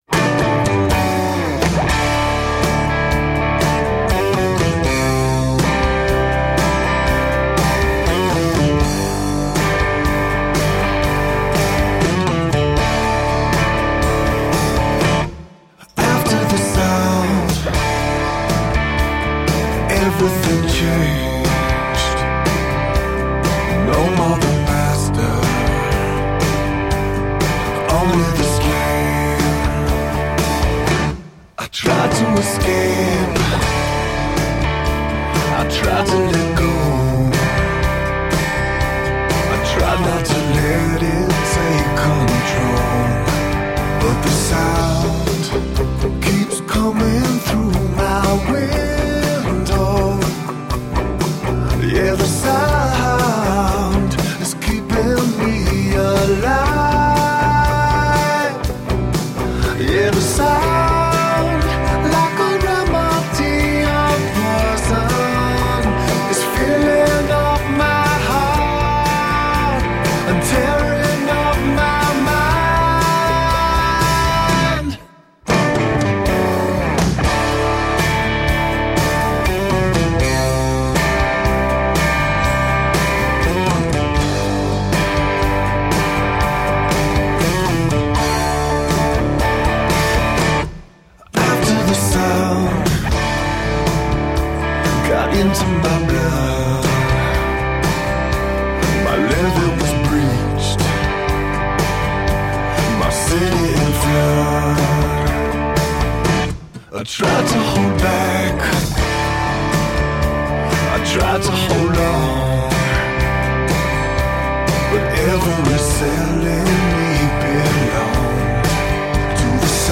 Masterfully nuanced aaa rock.